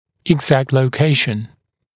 [ɪg’zækt ləu’keɪʃn][иг’зэкт лоу’кейшн]точная локализация, точное расположение